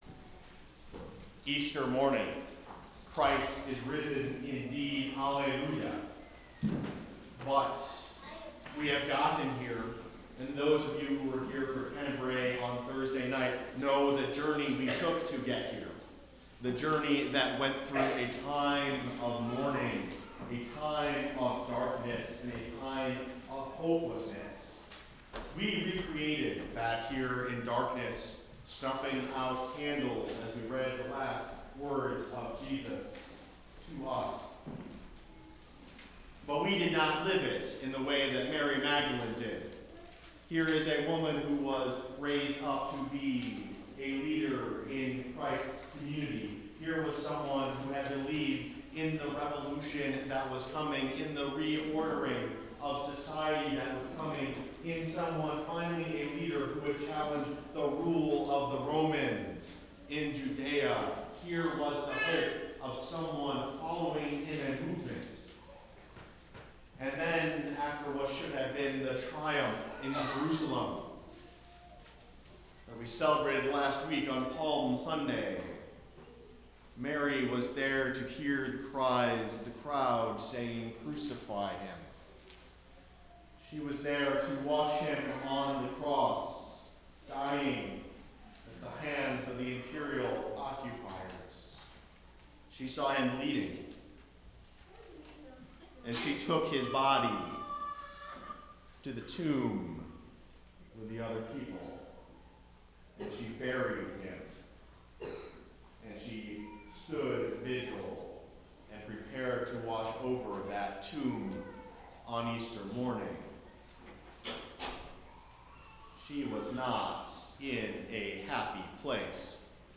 Preached April 20 (Easter!), 2014 in Ankeny UCC
Sermon